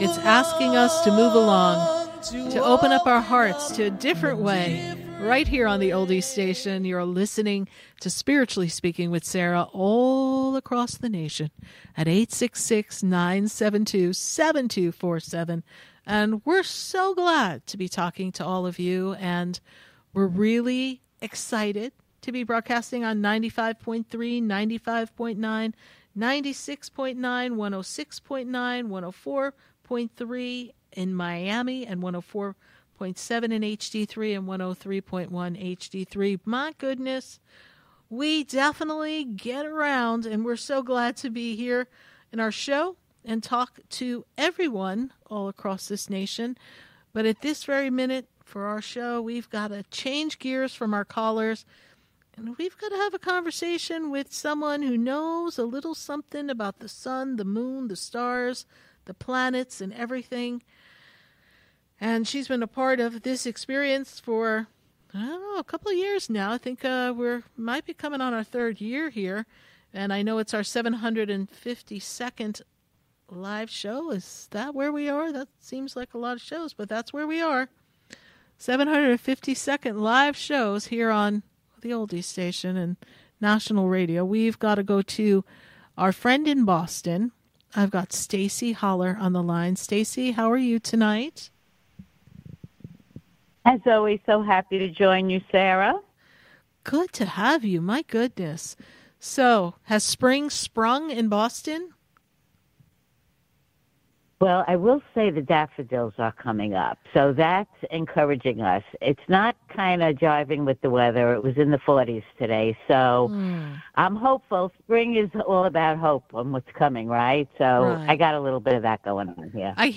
LIVE on the radio